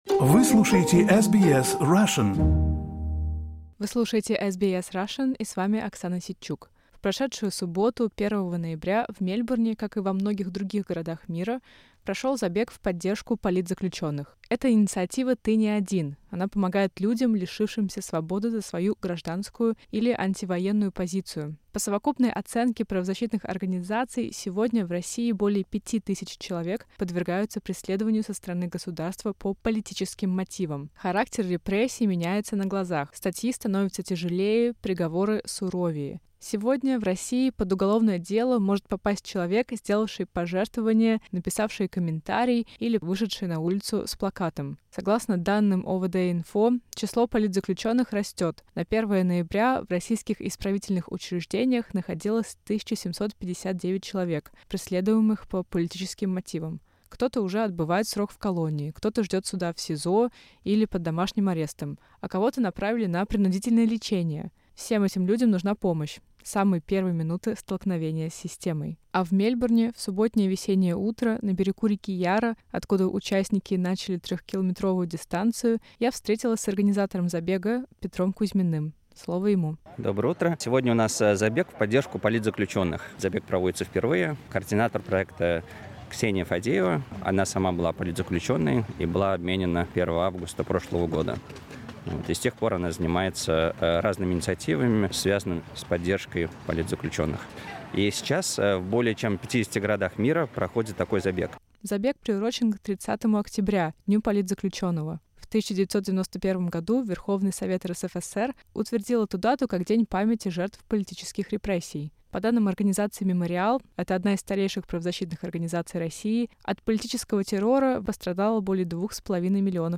1 ноября в Мельбурне прошёл забег «Ты не один» в поддержку политзаключённых — мероприятие, приуроченное к Дню политзаключённого, который в России отмечается 30 октября. Мы поговорили с участниками забега, которые вышли на трёхкилометровую дистанцию, чтобы выразить солидарность с теми, кого затронули репрессии.